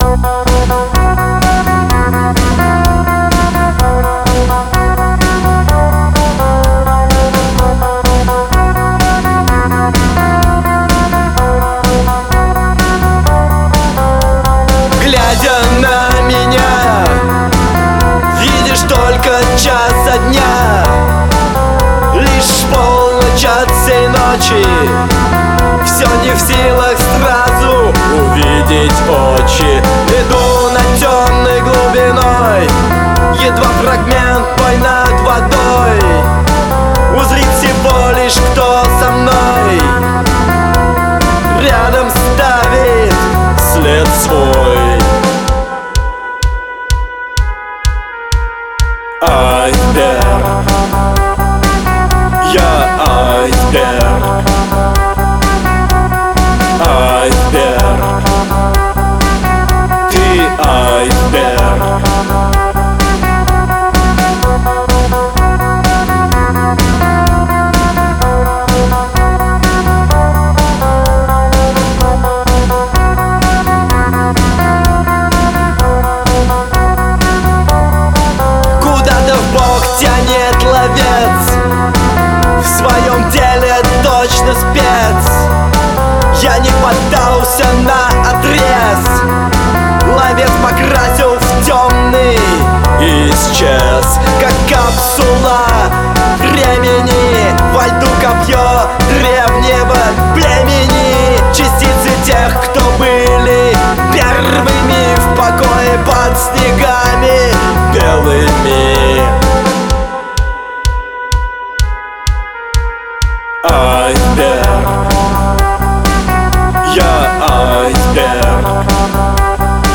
Атмосферный пост-панк с энергичным ритмом.